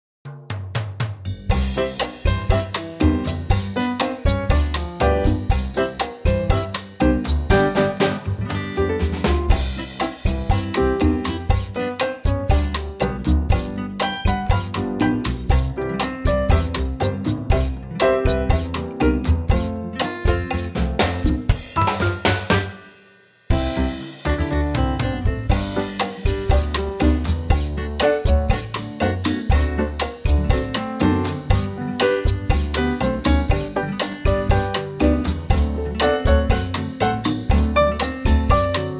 key C (the verses change to the key of G) 2:16
key - C - vocal range - F# to A
in a superb Quartet plus latin percussion arrangement